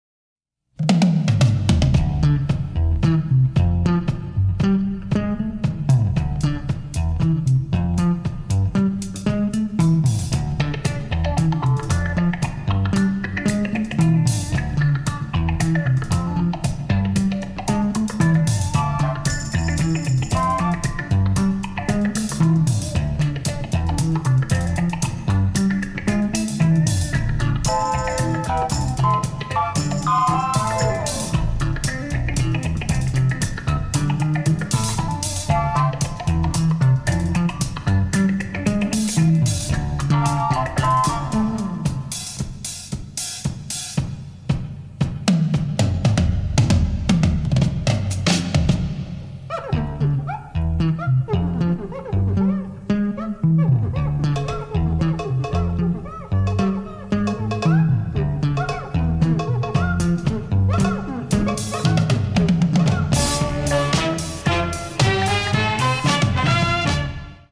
The score was created with a big band